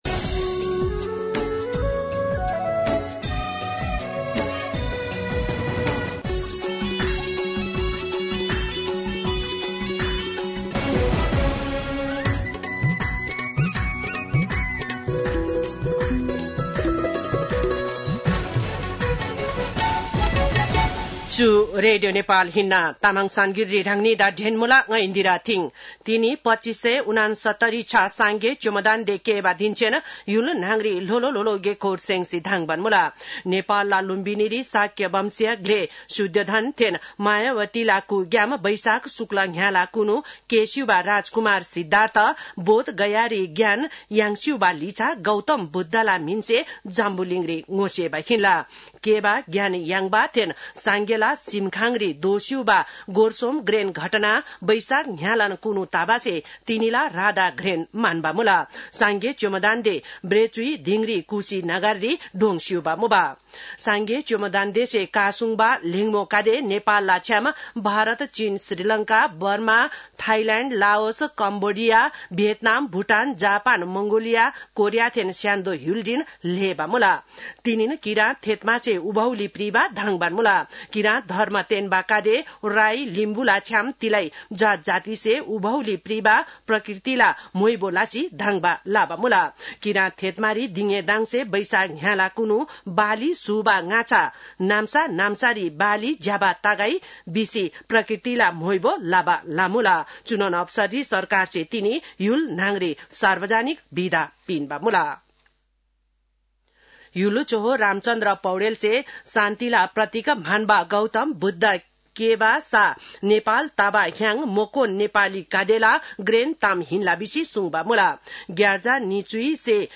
तामाङ भाषाको समाचार : २९ वैशाख , २०८२
5.5-pm-tamang-news.mp3